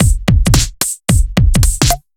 Index of /musicradar/off-the-grid-samples/110bpm
OTG_Kit 2_HeavySwing_110-B.wav